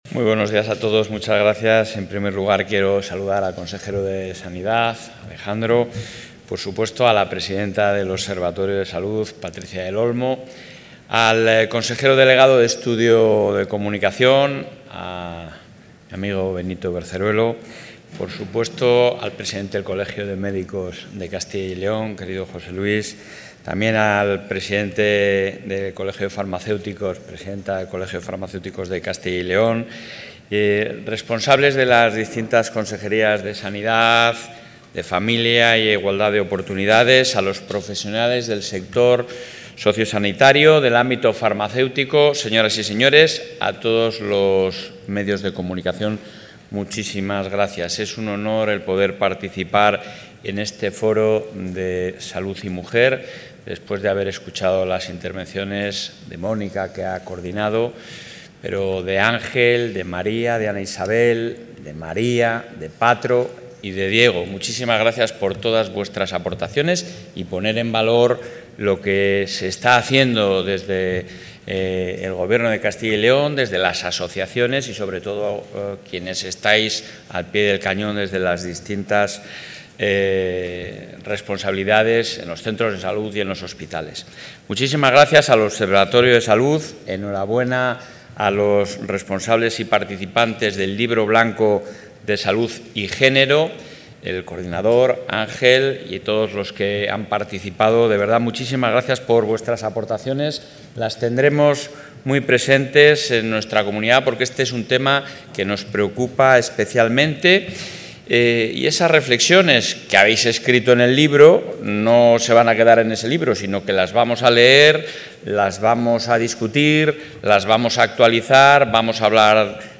El presidente de la Junta de Castilla y León, Alfonso Fernández Mañueco, ha participado hoy en Valladolid en el Foro Salud y...
Intervención del presidente de la Junta.